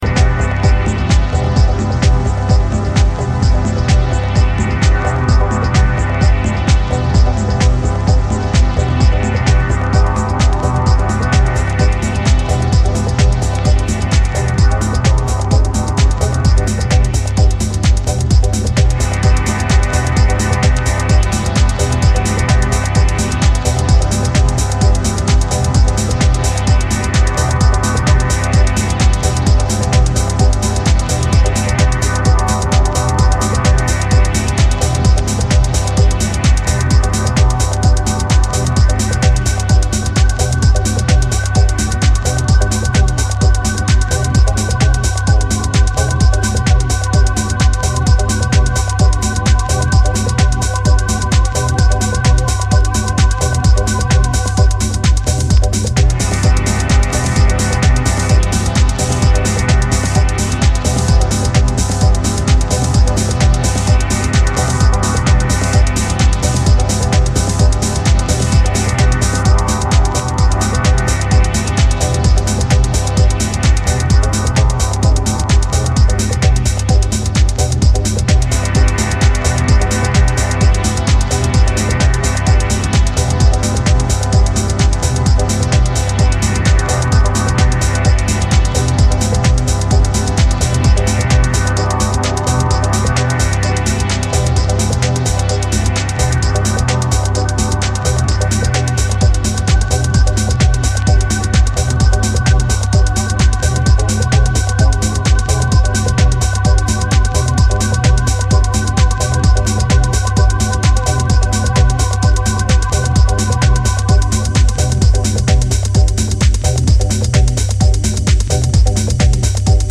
unique, energetic house / techno tracks
Deep house